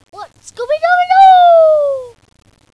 chain.wav